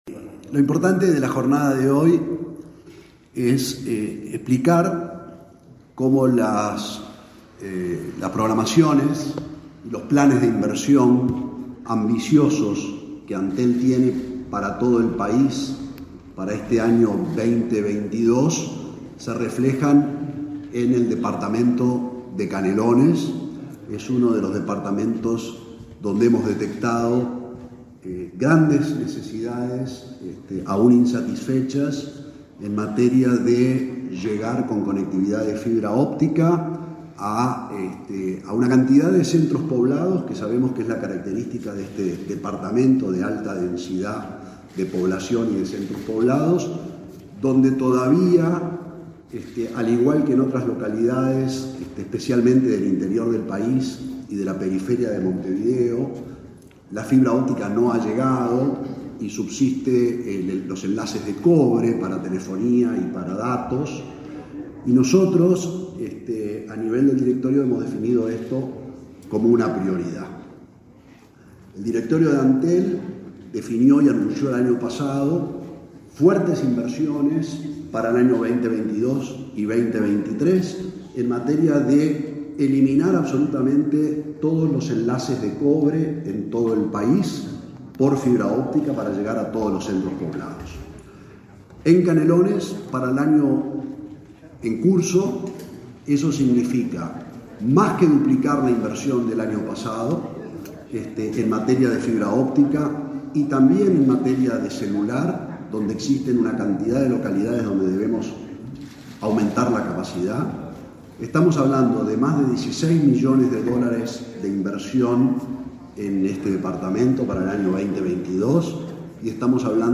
Palabras del presidente de Antel, Gabriel Gurméndez